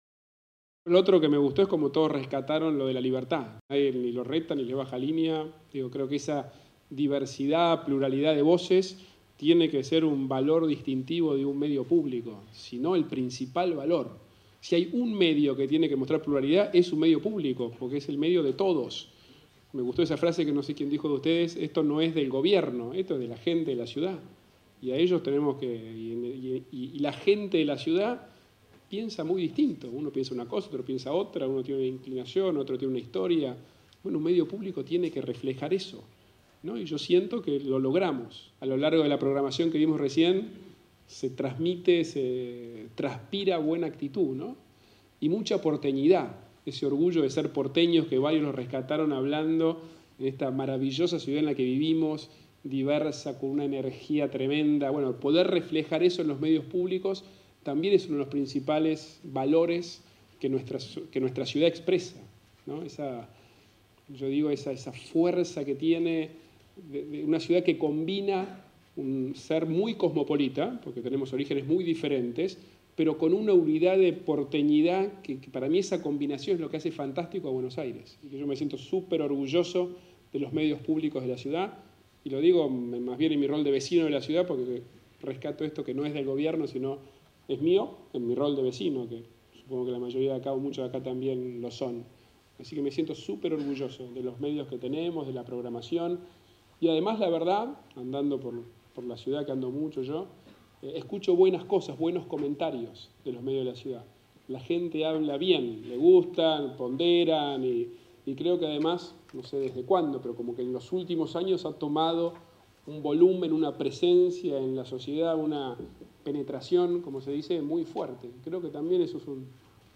El jefe de Gobierno de la Ciudad de Buenos Aires, Horacio Rodríguez Larreta, presentó la nueva programación de los medios públicos porteños para 2016.